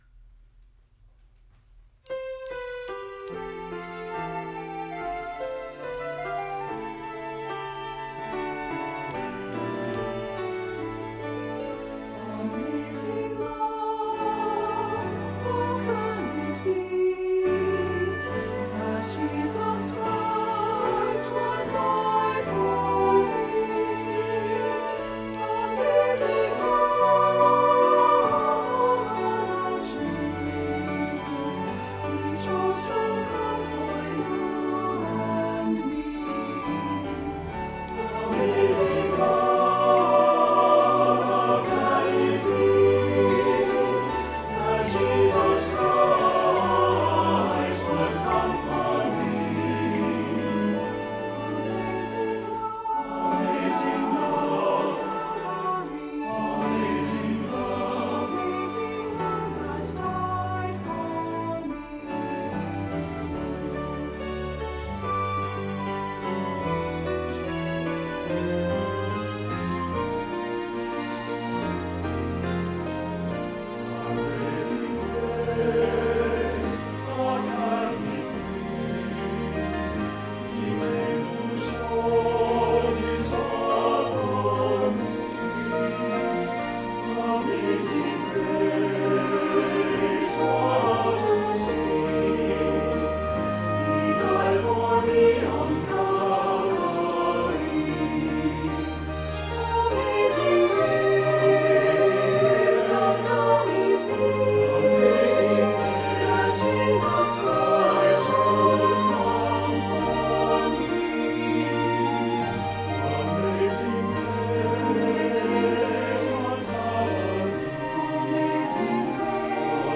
2026 Choral Selections